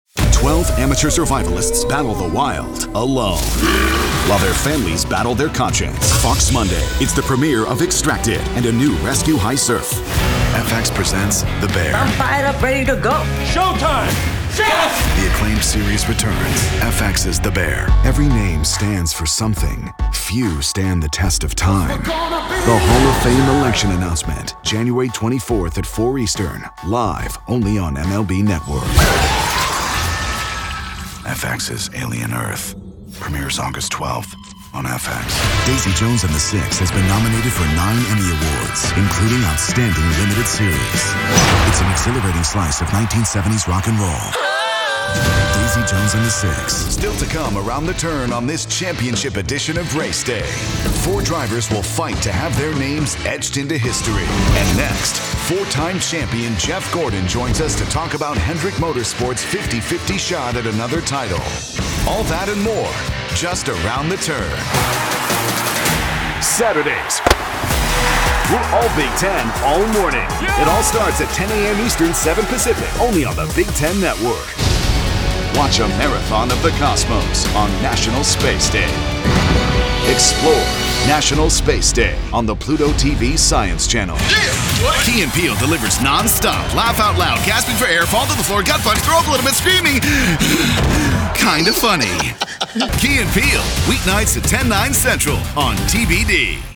Promo Demo